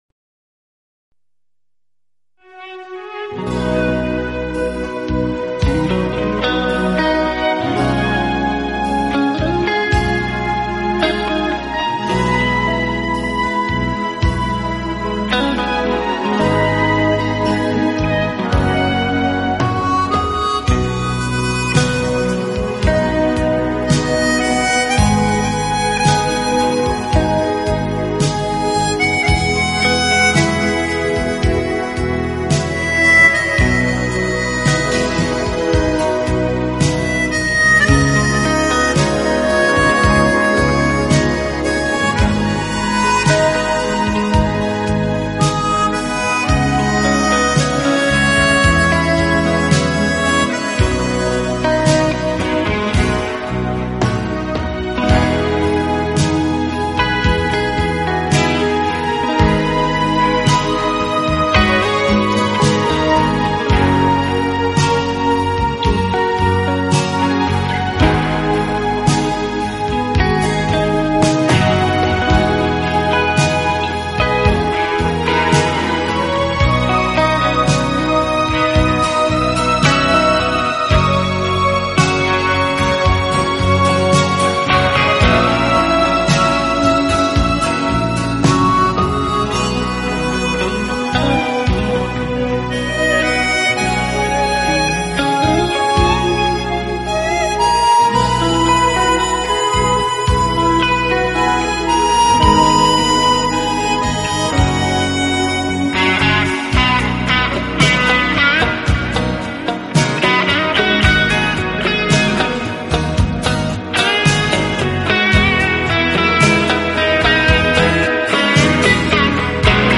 【浪漫口琴】
Genre: Instrumental
明快清新的演奏，脍炙人口的乐曲，一串串跳跃的音符随着口琴的吹奏变得更加生动。